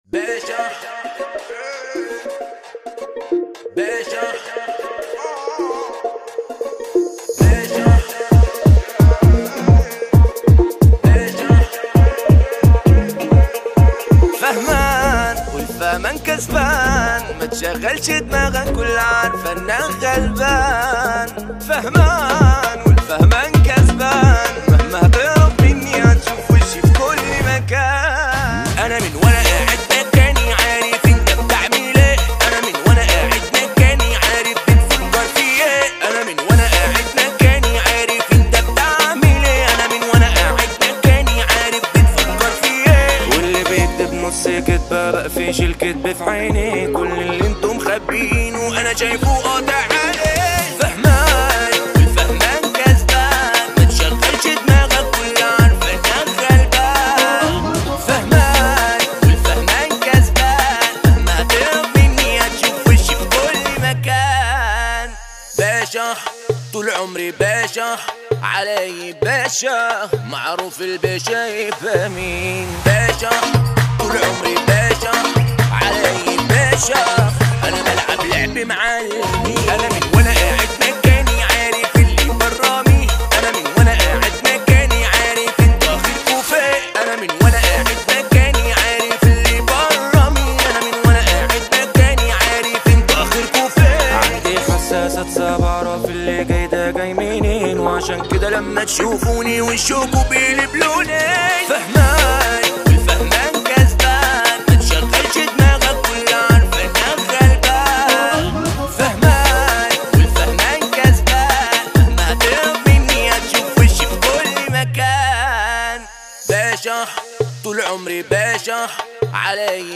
جيتار